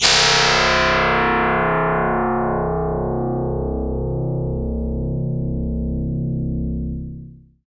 SI2 KREETCH.wav